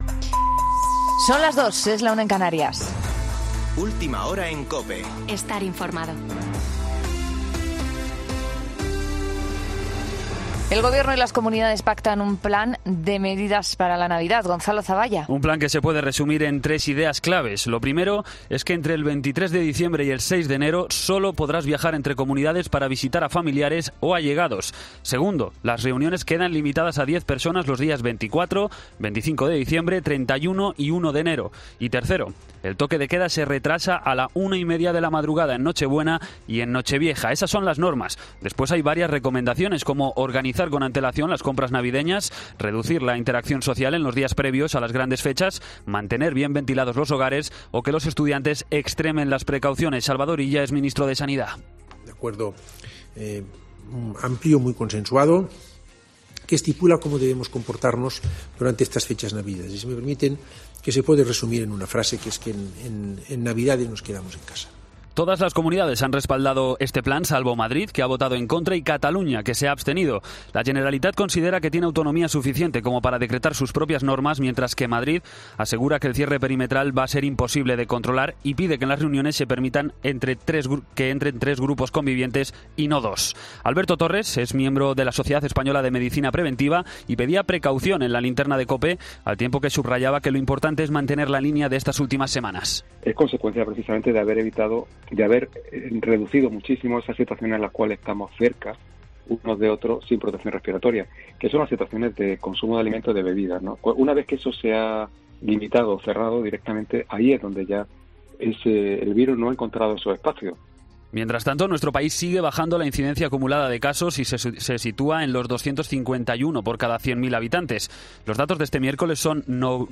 Boletín de noticias COPE del 3 de diciembre de 2020 a las 02.00 horas